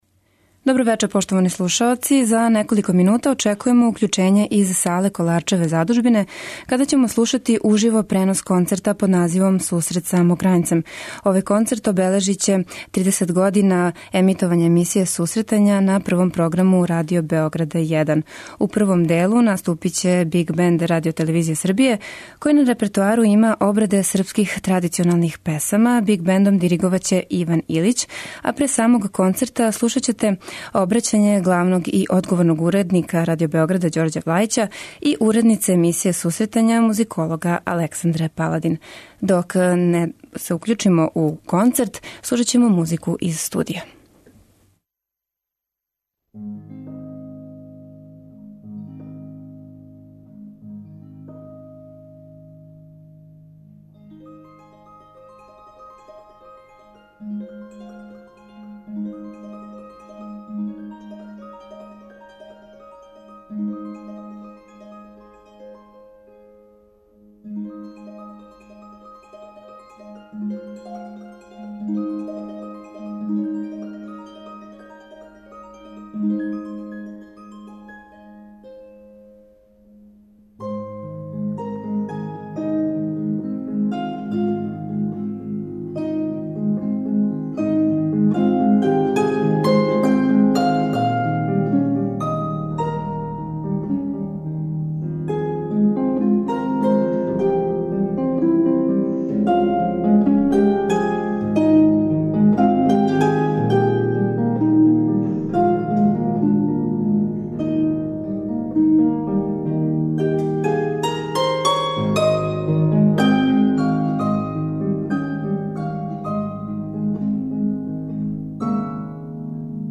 Концерт можете пратити у директном преносу од 20-22 часа на програму Радио Београда 1.
koncert.mp3